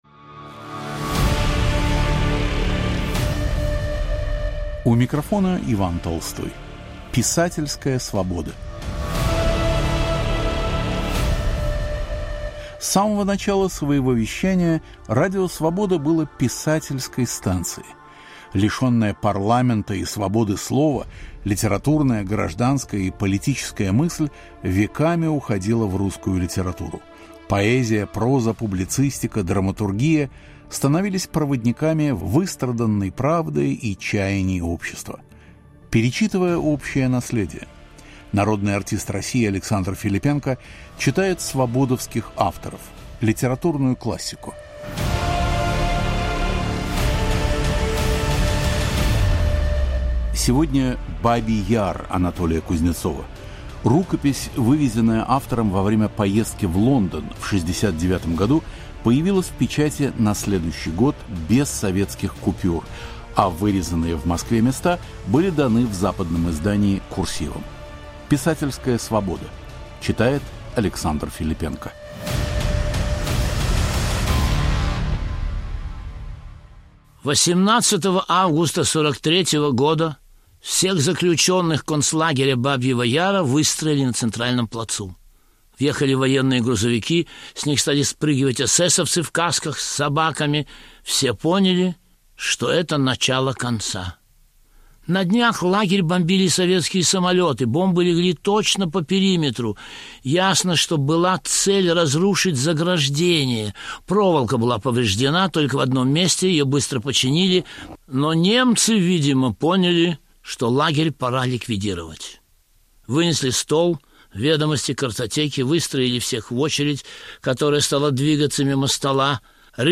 Александр Филиппенко читает фрагмент из книги Анатолия Кузнецова "Бабий Яр"